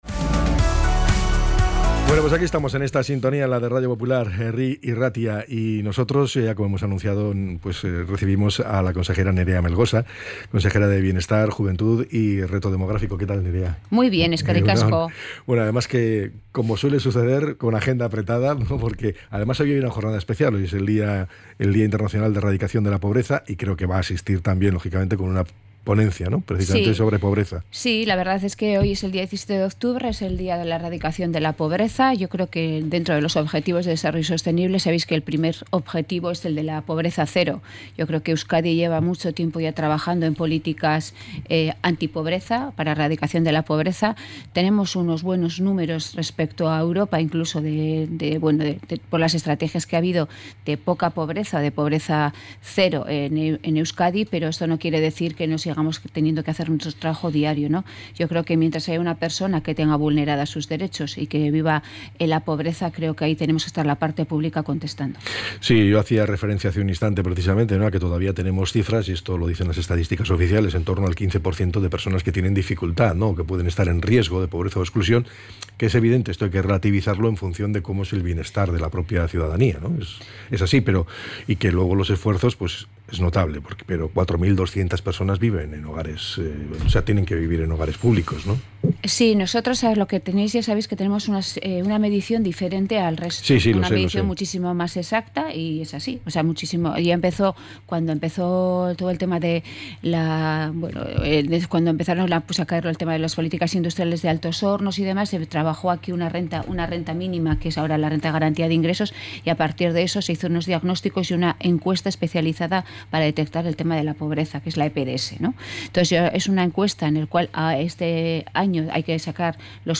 ENTREV.-NEREA-MELGOSA.mp3